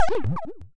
player_death.wav